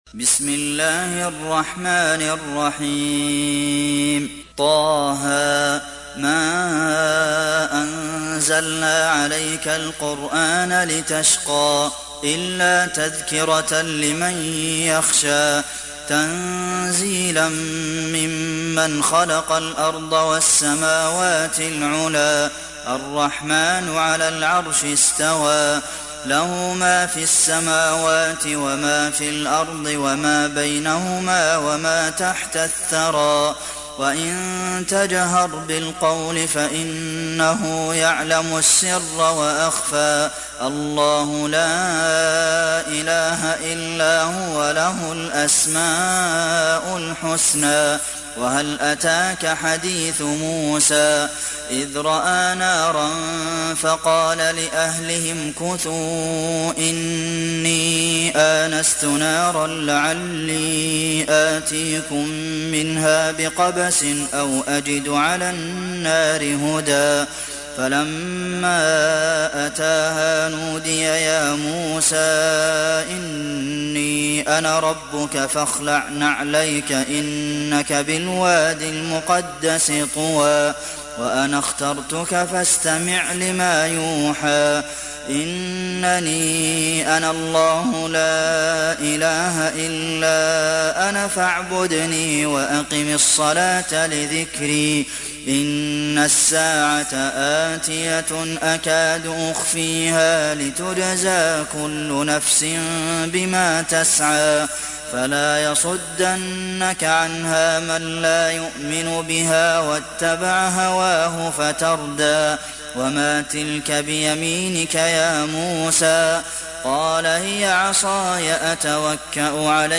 تحميل سورة طه mp3 بصوت عبد المحسن القاسم برواية حفص عن عاصم, تحميل استماع القرآن الكريم على الجوال mp3 كاملا بروابط مباشرة وسريعة